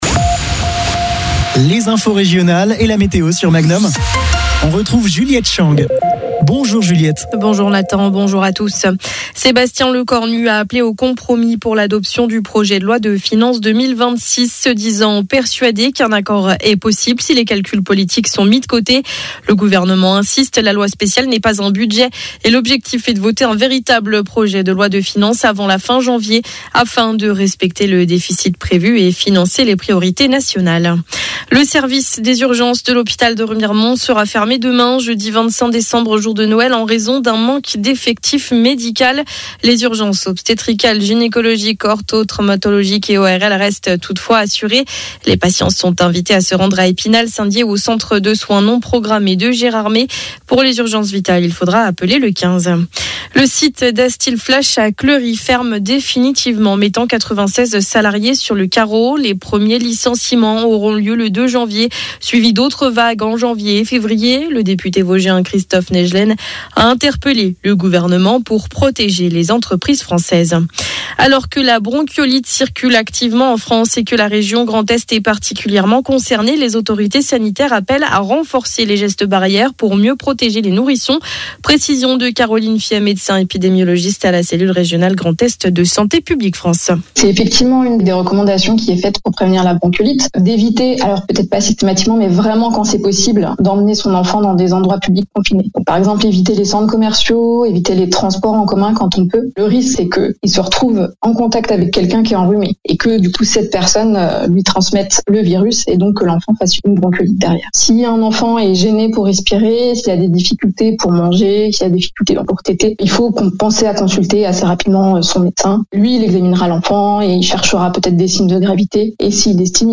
flash infos